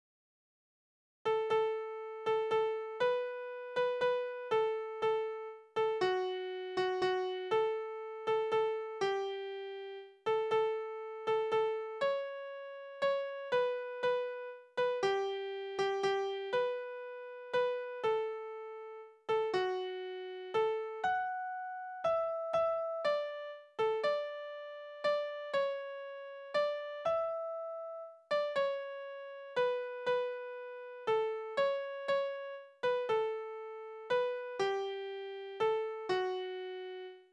Balladen: Das Kind macht dem verlassenen Mädchen neuen Lebensmut
Tonart: D-Dur
Taktart: 3/4
Tonumfang: Oktave